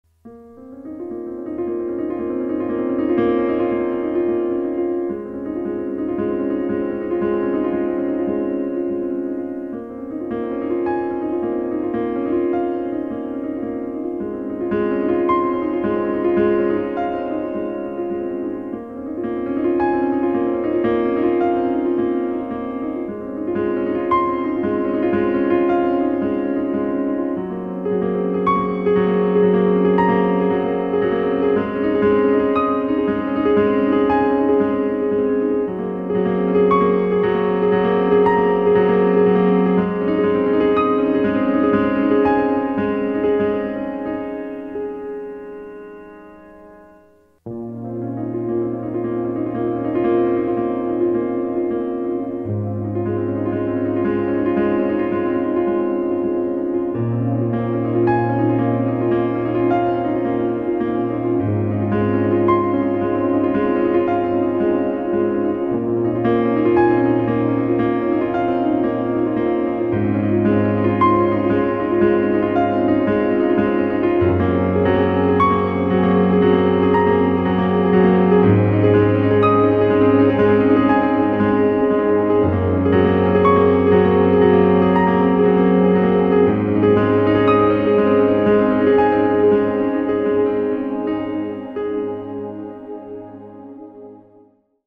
Besetzung: Klavier
Tempo / Satzbezeichnung: Freely
Tonart: D-Dur / b-Moll